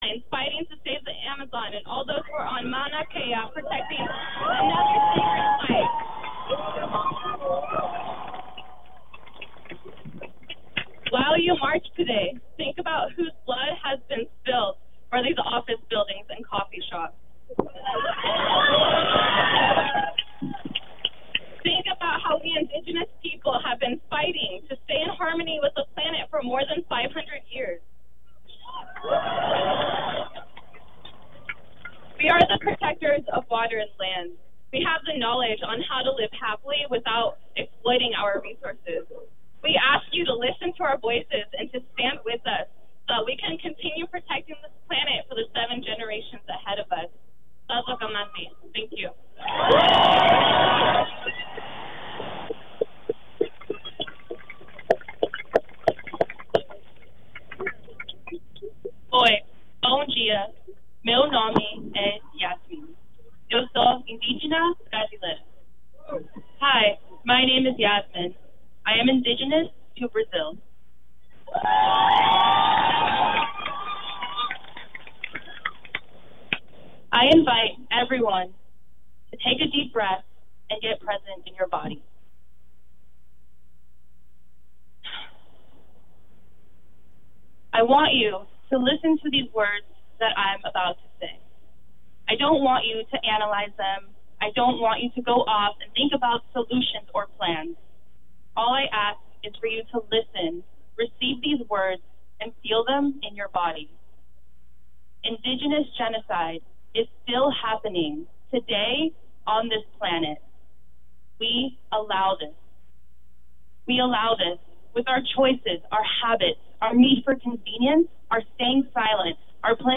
------ Here we feature episodes that feature incisive interviews, speeches, thoughts, opinion and convergences. ------